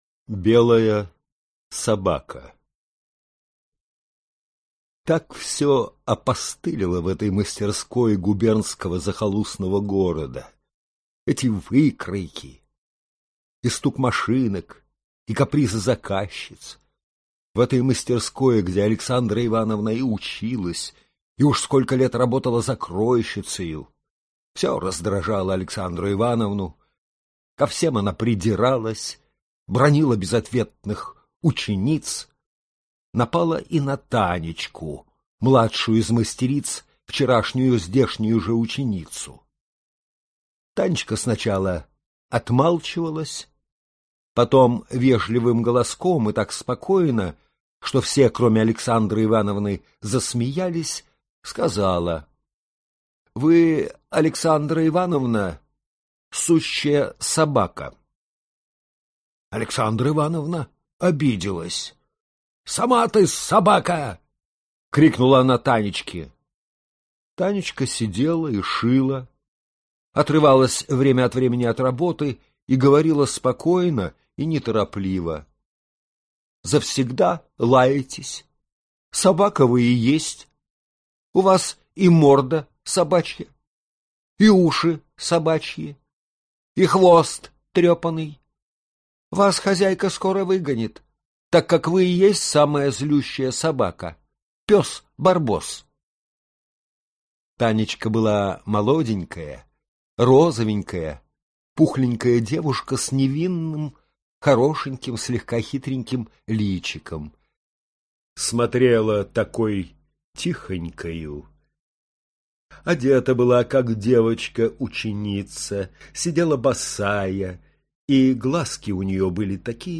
Аудиокнига Дама в узах | Библиотека аудиокниг